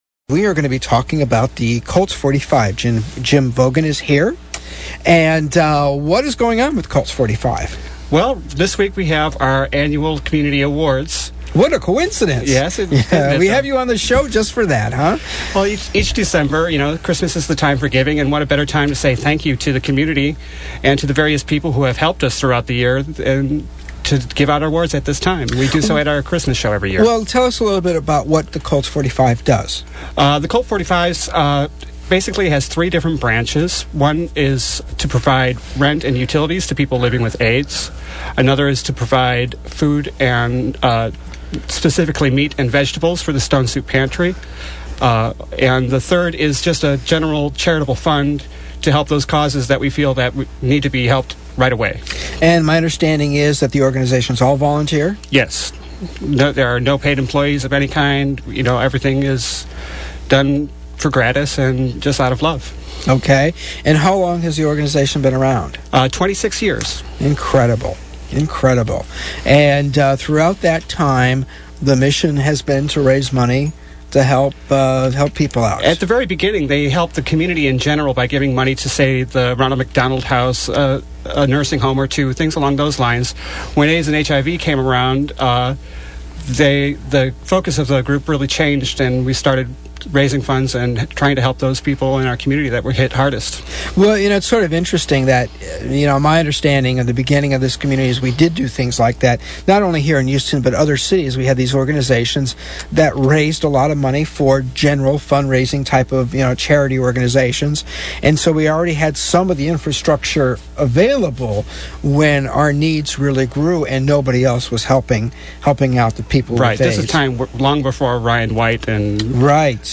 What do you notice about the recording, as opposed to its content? Radio appearance on KPFT's Queer Voices